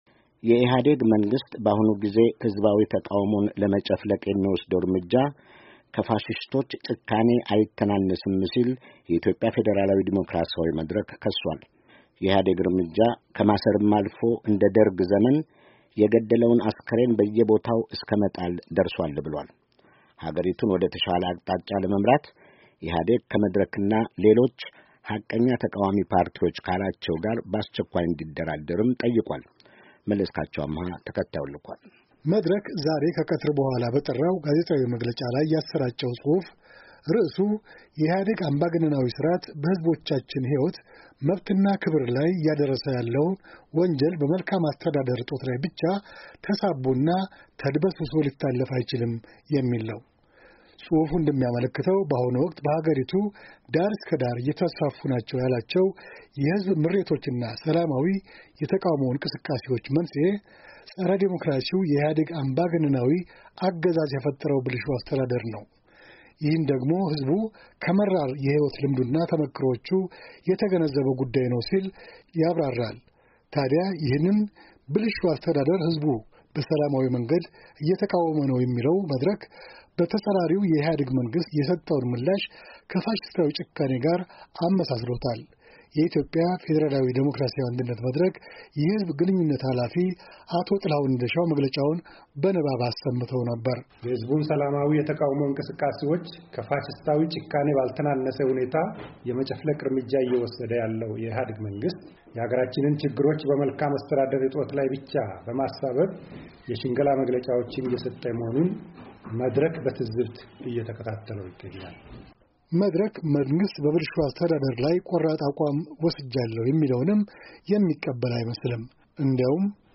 አዲስ አበባ —
የትላንቱ የኢትዮጵያ ፌድራላዊ አንድነት መድረክ ጋዜጣዊ መግለጫ